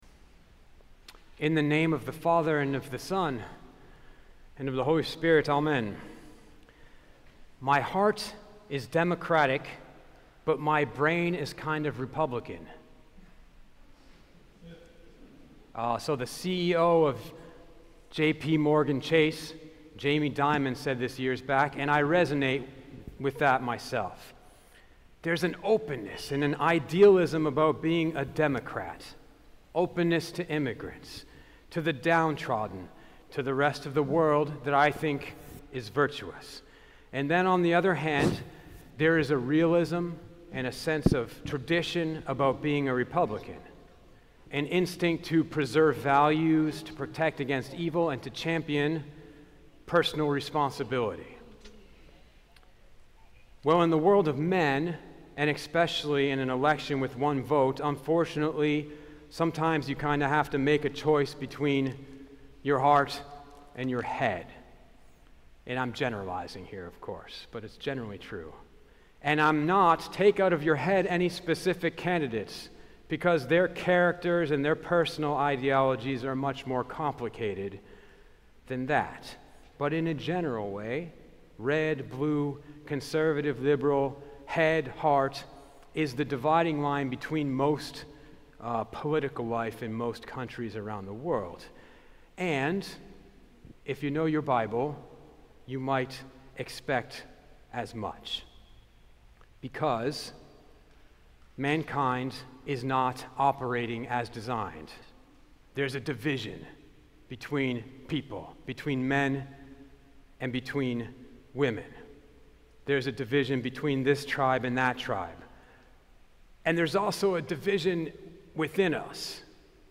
Podcasts Sermons St. Hagop Armenian Church